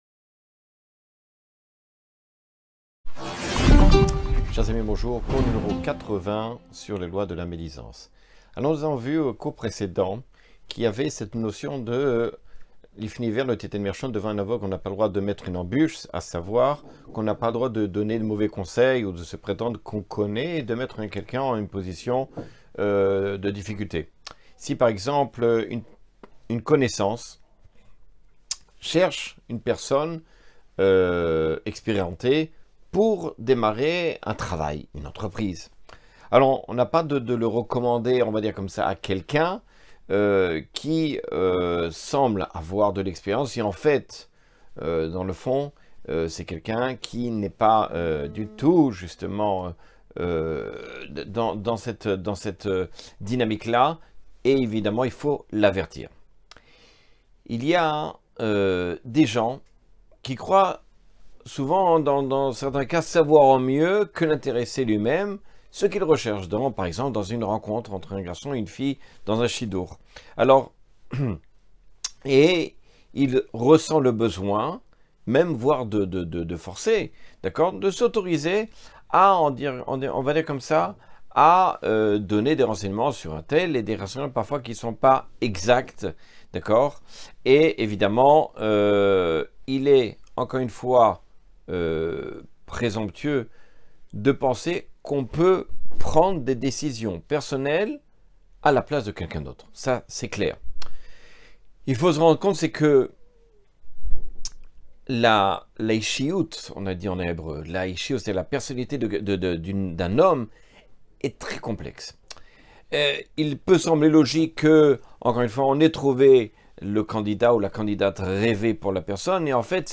Les lois du lashon hara : Cours 80 - Beth Haketiva
Cours 80 sur les lois du lashon hara.